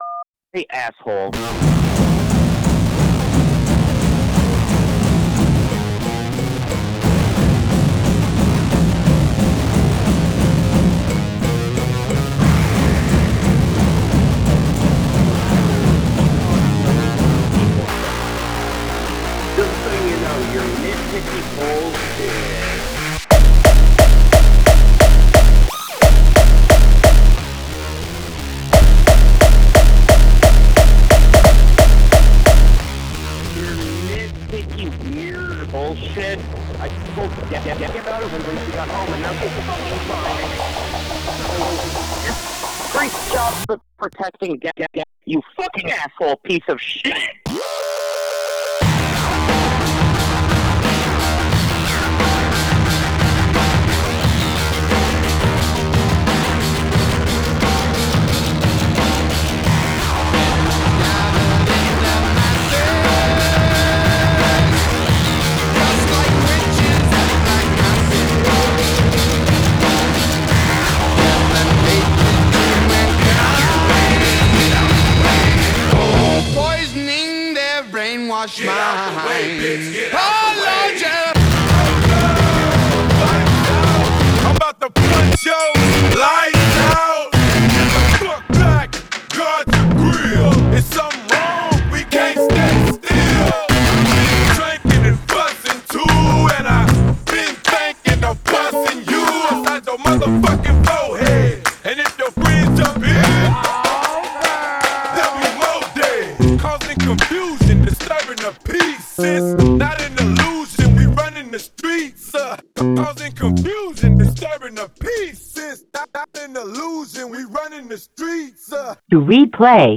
Cool use of the drum smashing and overdistorted blips!